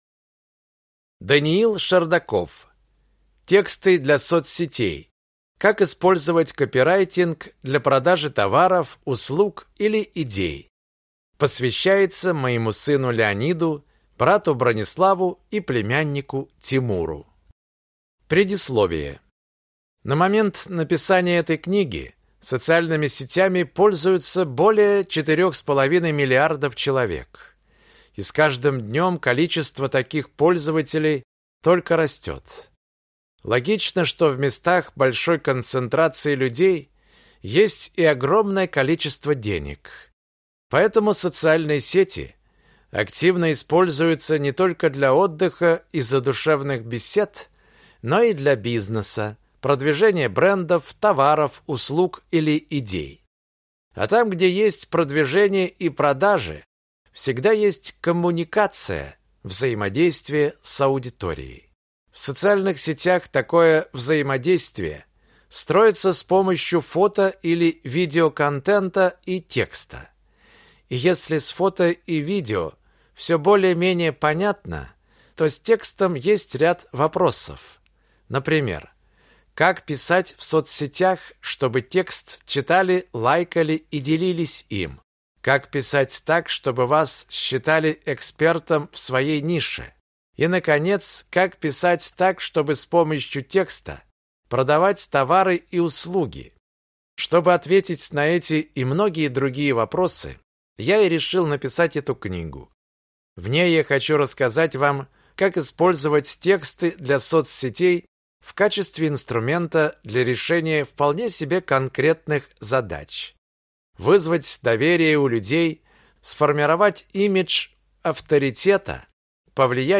Аудиокнига Тексты для соцсетей. Как использовать копирайтинг для продажи товаров, услуг или идей | Библиотека аудиокниг